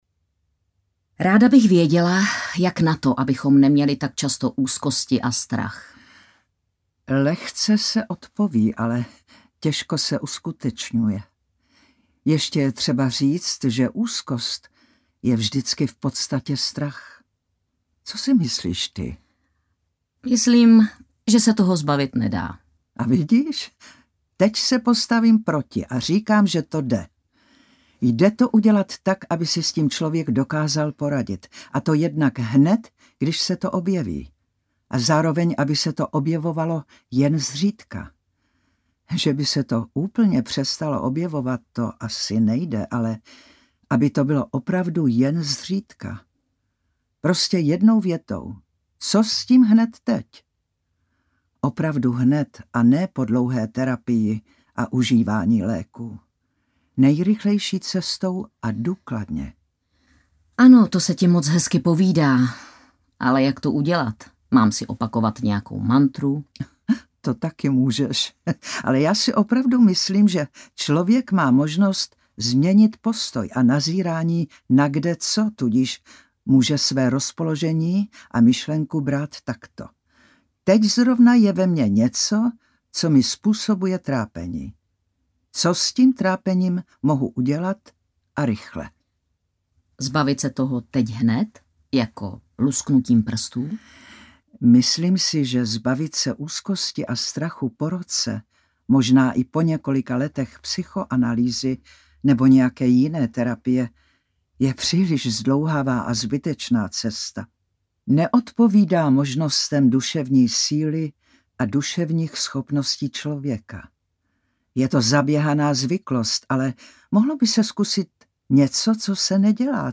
Pocity jsou lež - rebelie v psychologii audiokniha
Ukázka z knihy